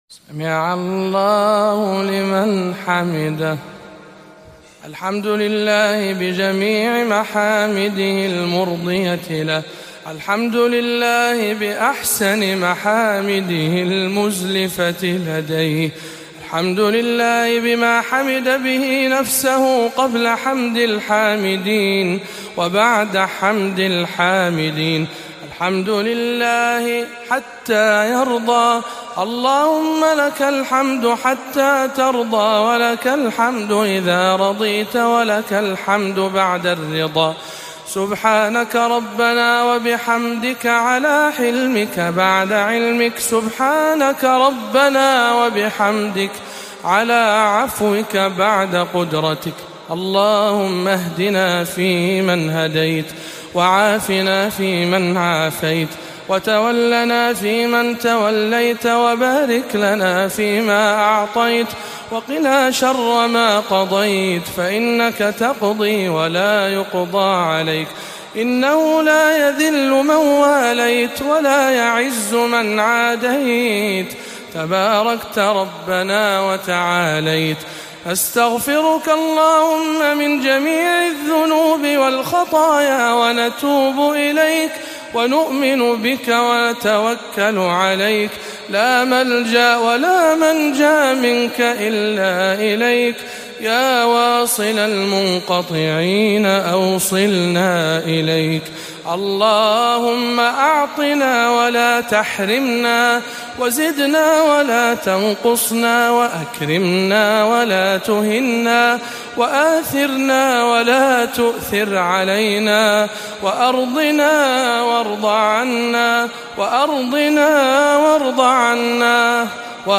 أدعية القنوت